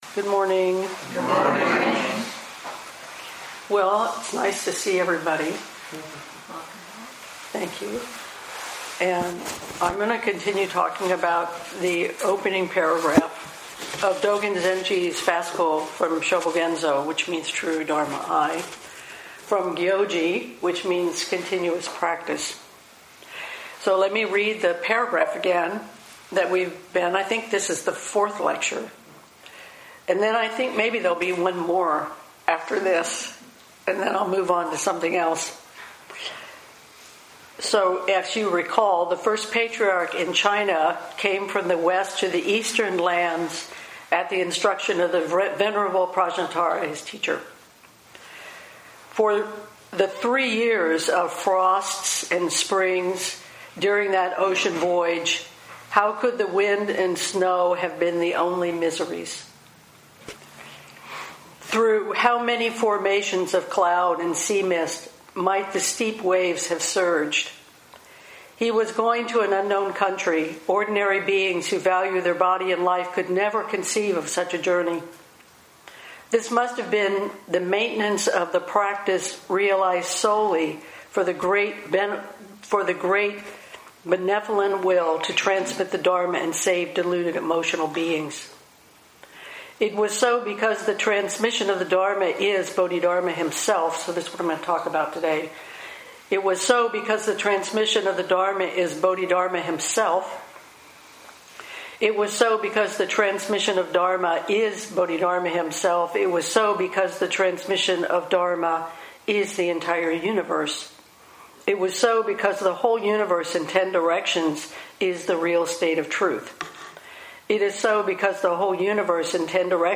Bodhidharma’s Vow, Gyoji Lecture part 4
2018 in Dharma Talks